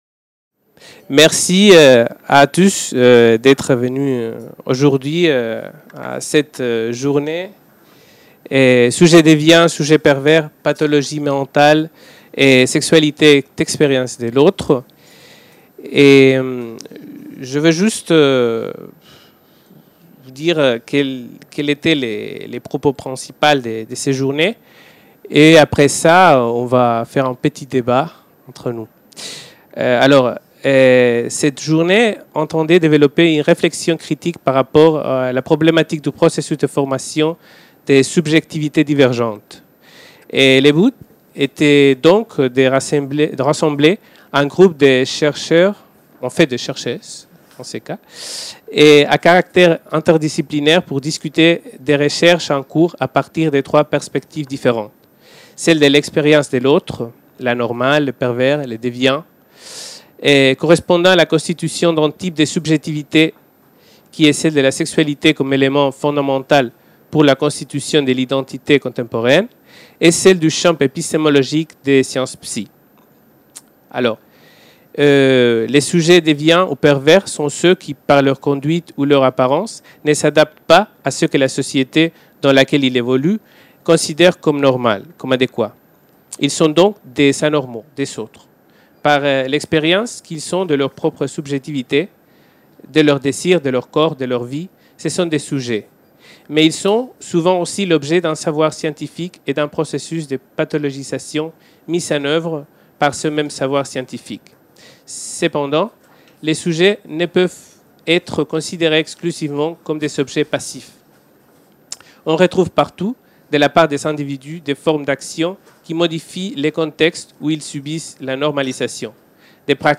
Journée d'études - Table ronde Sujets déviants, sujets pervers Pathologie mentale, sexualité et expérience de l'autre | Canal U